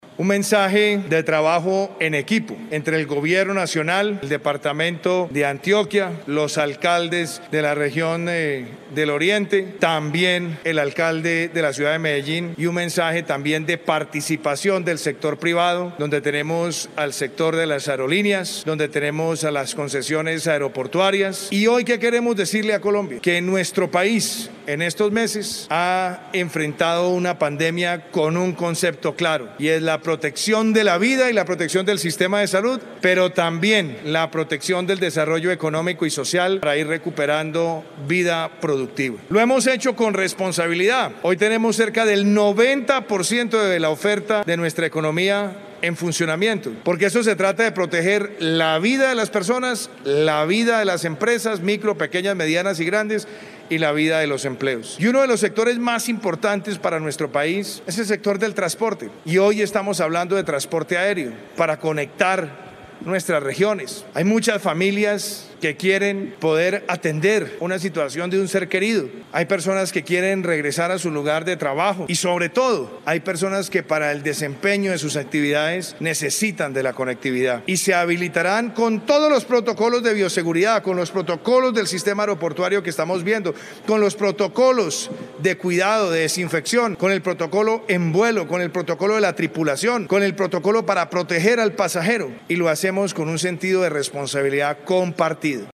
Descargue audio: Iván Duque Márquez, presidente de Colombia